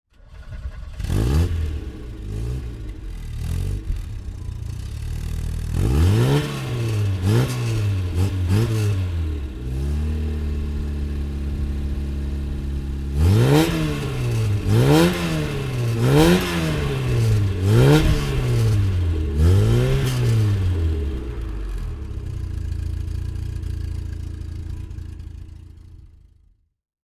Fiat 128 rally (1974) - Starten und Leerlauf